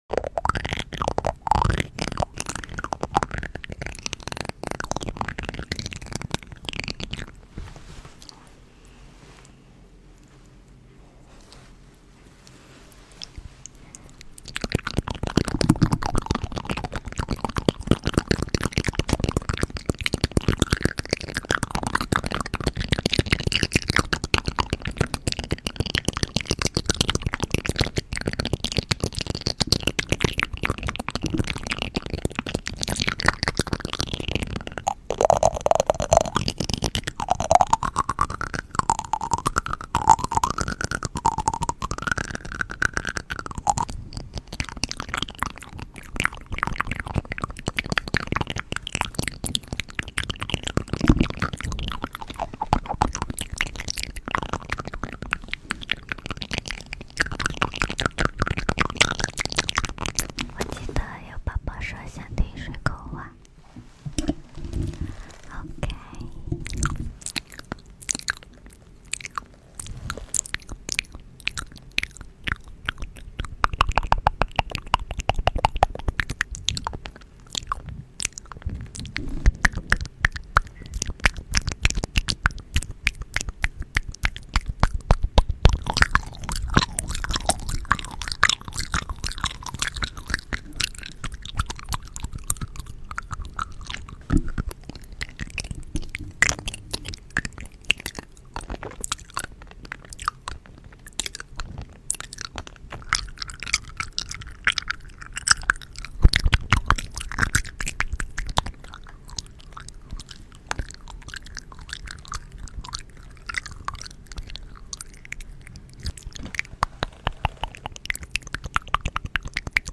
Asmr touch pronunciation oral sounds